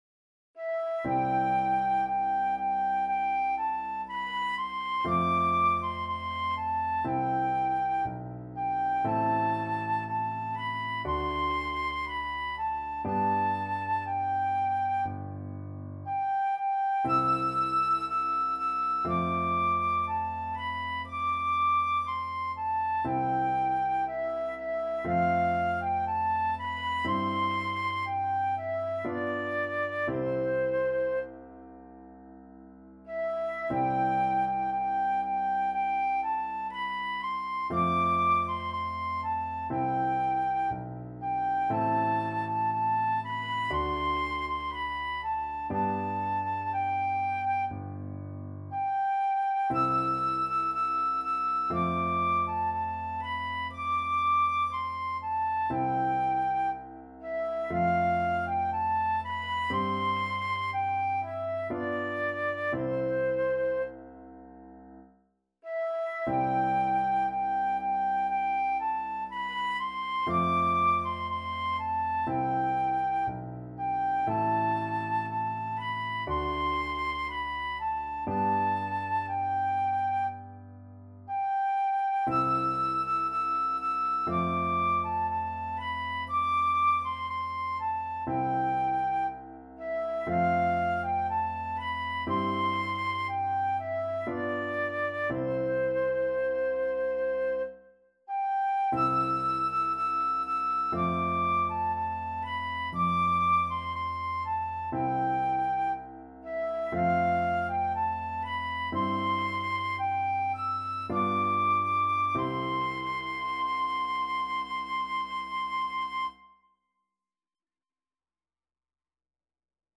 Flute
C major (Sounding Pitch) (View more C major Music for Flute )
~ = 100 Adagio
4/4 (View more 4/4 Music)
Classical (View more Classical Flute Music)